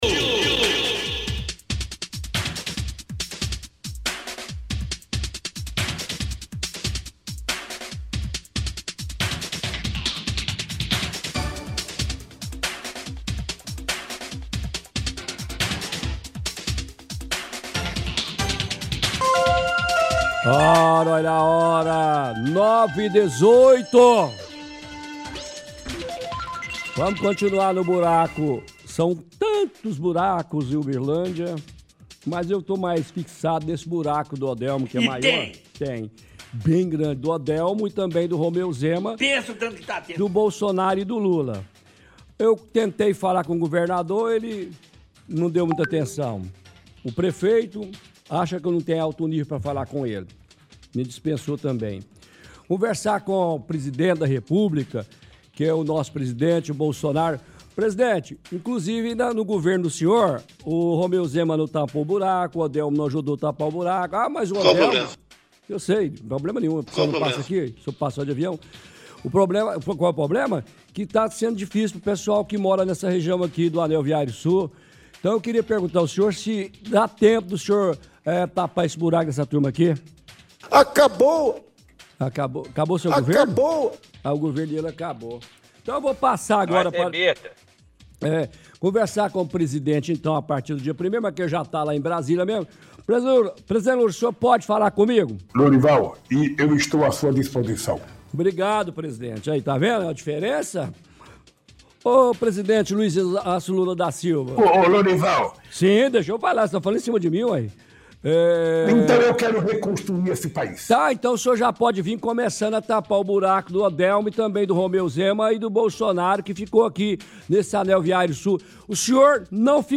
-Veicula Voz do Prefeito Odelmo Leão: “Quer conversar coisa de alto nível comigo, eu estou à disposição”.
-Veicula mais vozes do Prefeito Odelmo Leão – “Não foi Possível” e “Mas essa é a verdade”.
-Faz ironias em relação à cratera utilizando a voz do Presidente Lula.